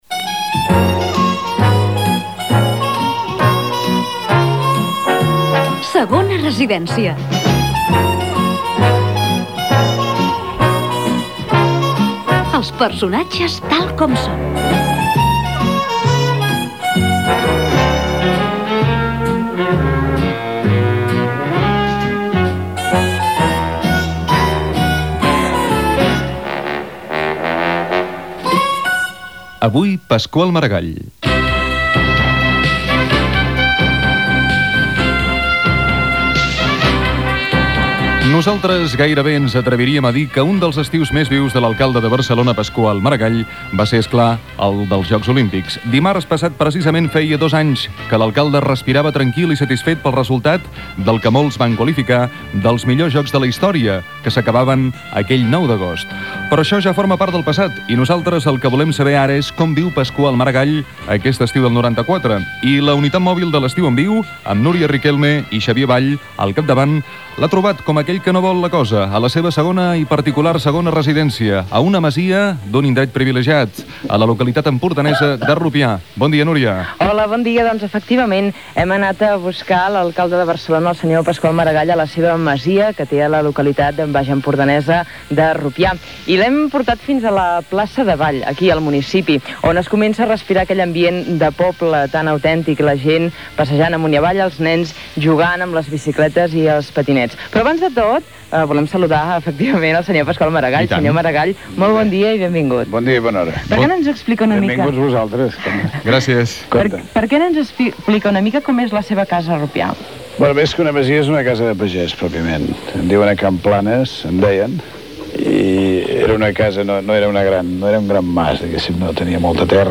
Per tothom: entrevista